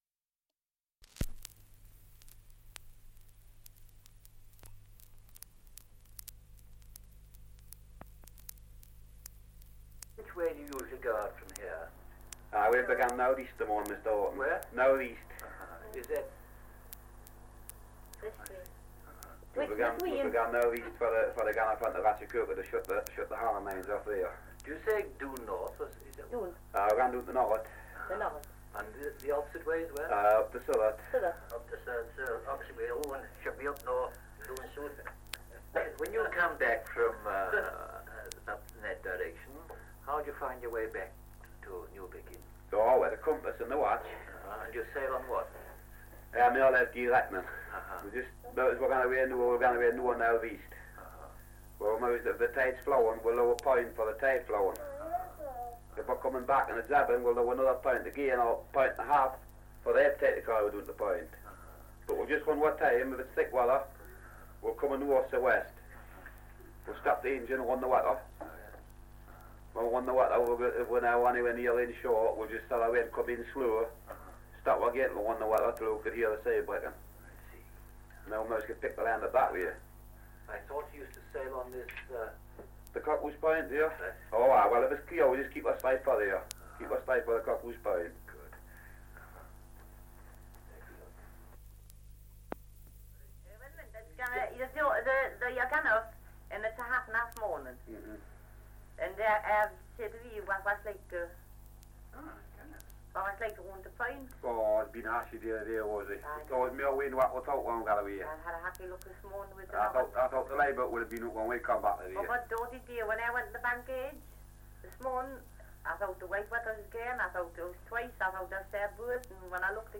Dialect recording in Newbiggin-by-the-Sea, Northumberland
78 r.p.m., cellulose nitrate on aluminium